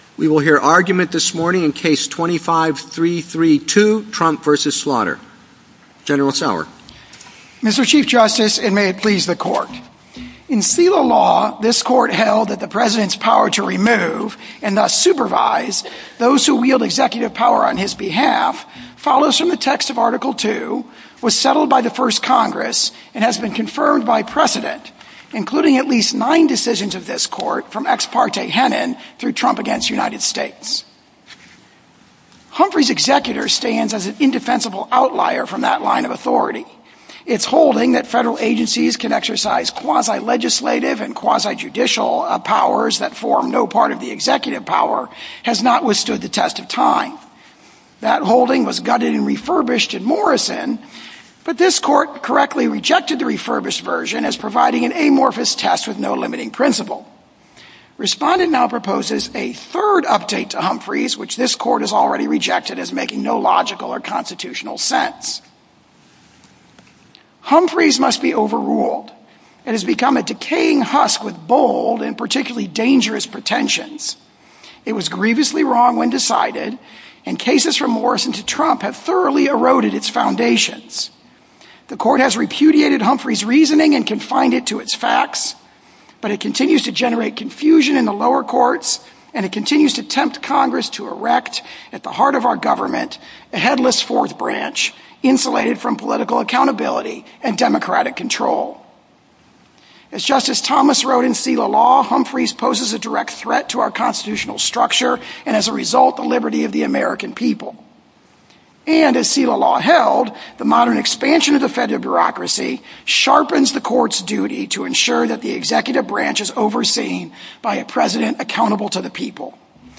Oral Arguments for the Supreme Court of the United States Trump, President of United States v. Slaughter Play episode Dec 8, 2025 2h 30m Bookmarks Episode Description Trump, President of United States v. Slaughter See all episodes